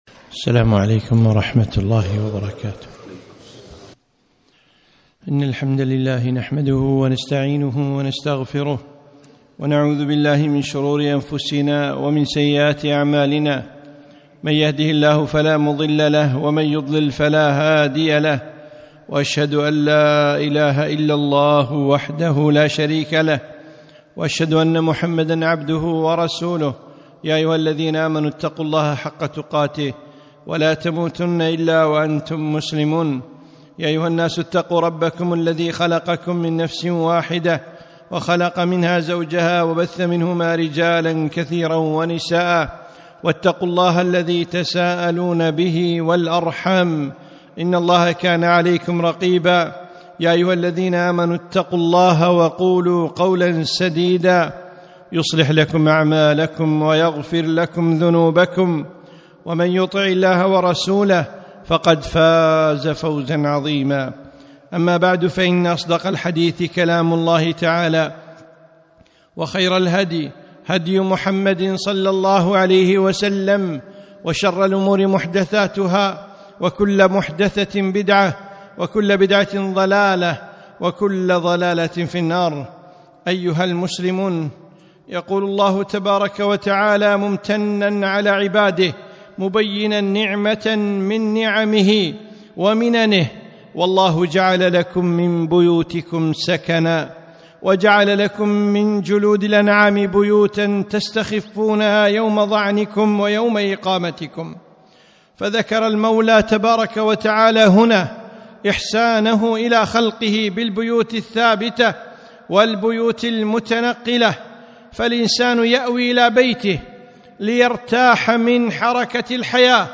خطبة - سكن البيوت